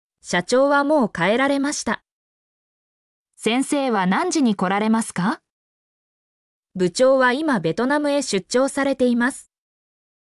mp3-output-ttsfreedotcom-12_EbTaqStp.mp3